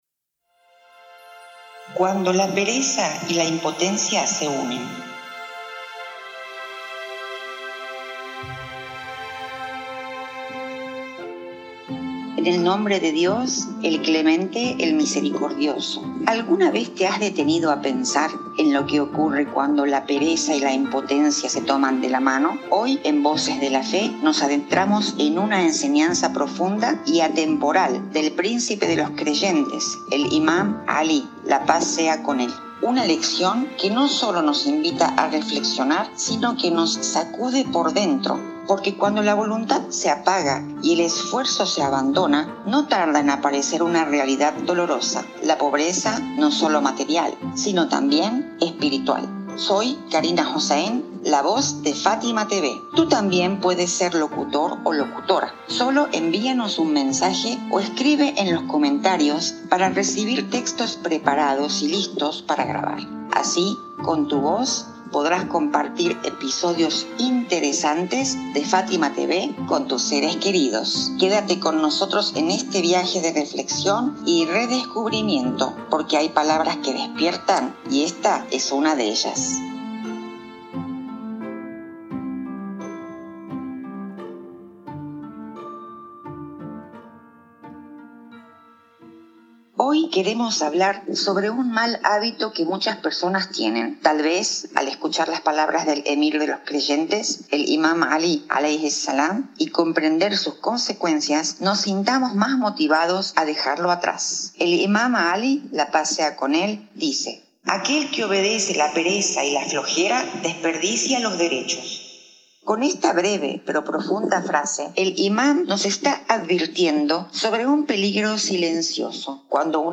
🎙 Locutora